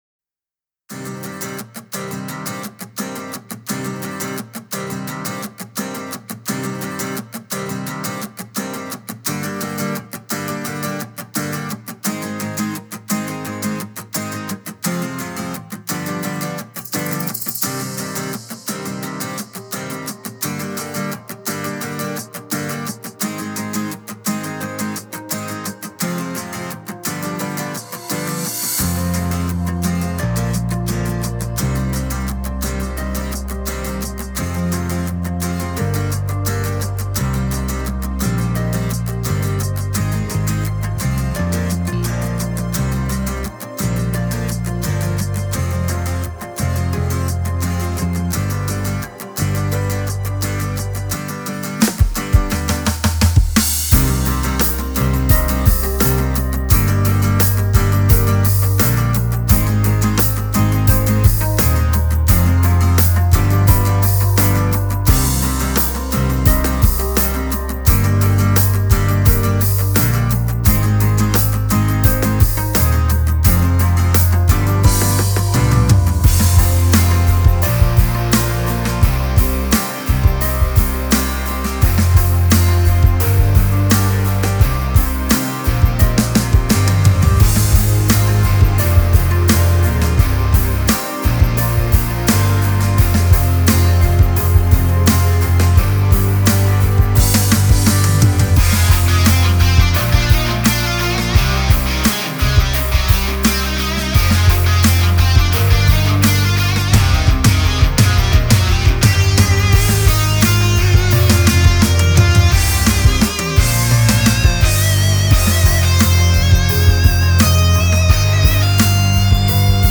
Всі мінусовки жанру Pop-Rock
Плюсовий запис